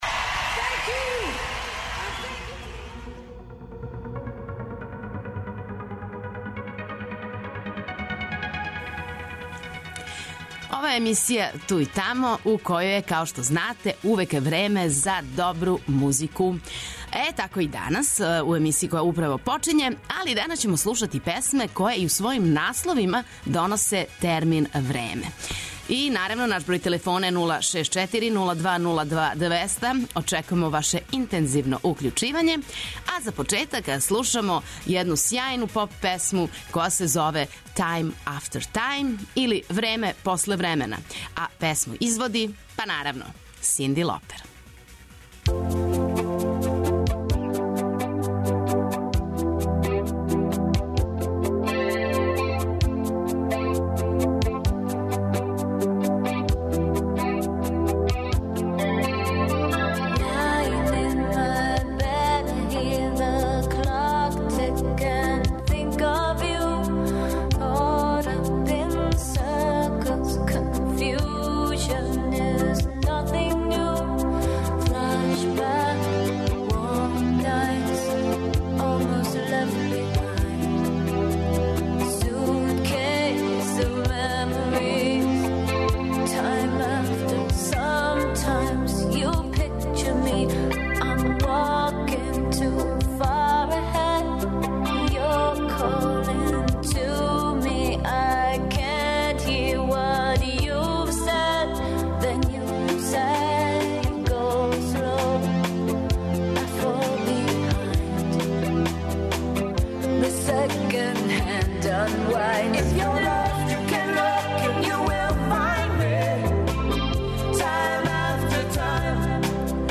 Док се будите и пијете јутарњу кафу обавезно појачајте 'Двестадвојку' јер вас очекују велики хитови страни и домаћи, стари и нови, супер сарадње, песме из филмова, дуети и још много тога.